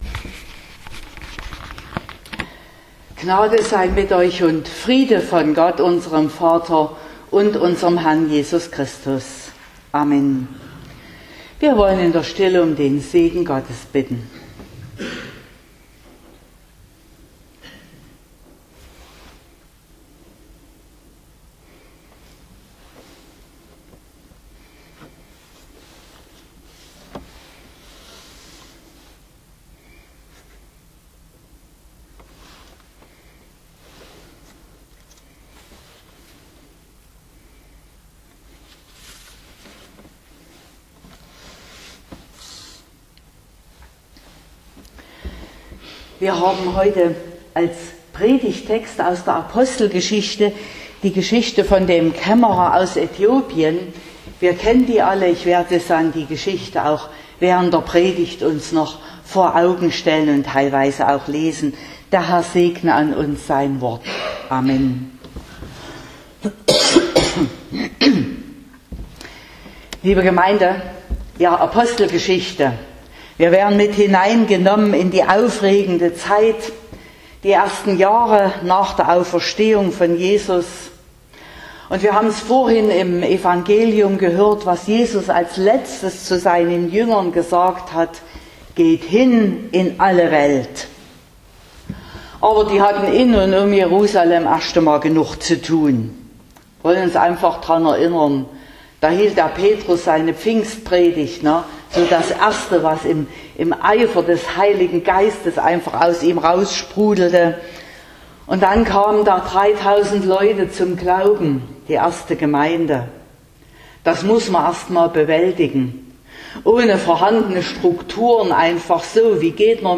07.07.2024 – Gottesdienst
Predigt (Audio): 2024-07-07_Kaemmerer_aus_Aethiopien_-_neue_Prioritaet_nach_dem_Reden_Gottes.mp3 (26,3 MB)